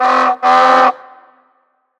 Klaxon
Long et grave classique